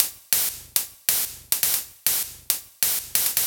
Luxury Hats 138bpm.wav